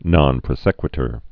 (nŏn prə-sĕkwĭ-tər, nōn)